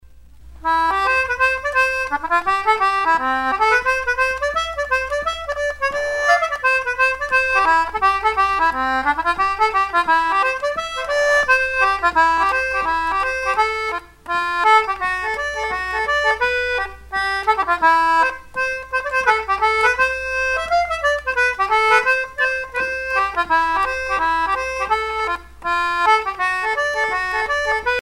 Sea chanteys and sailor songs
Pièce musicale éditée